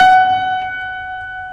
pyutest-note.ogg